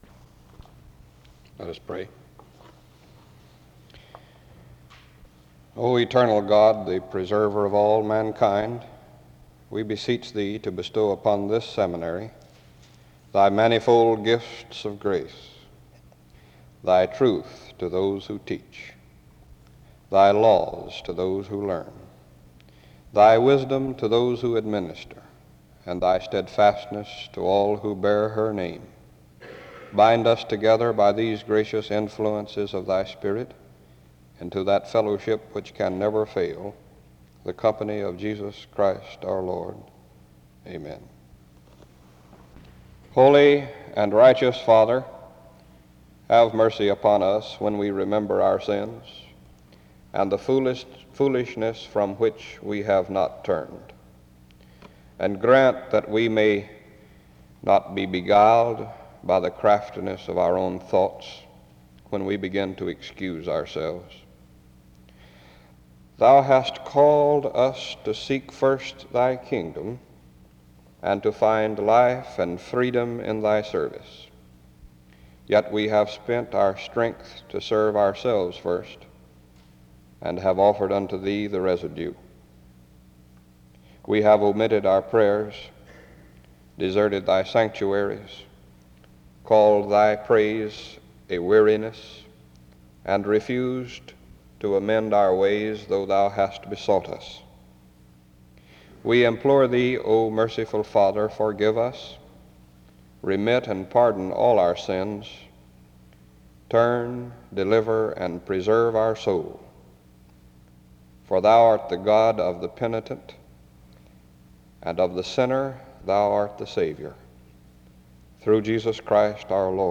SEBTS Chapel
SEBTS Chapel and Special Event Recordings SEBTS Chapel and Special Event Recordings